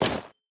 Equip_copper5.ogg